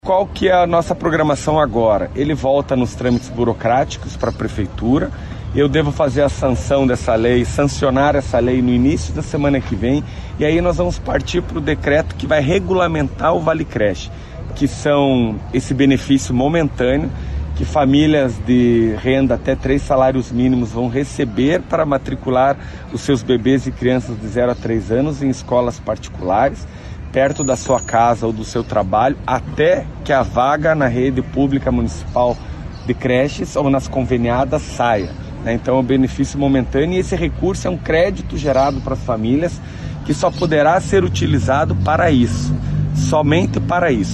Durante entrevista na manhã desta quarta-feira, quando visitou uma escola da rede municipal, que retorna para mais um ano letivo, o chefe do Executivo apontou quais serão os próximos passos após a aprovação do projeto pelo Legislativo.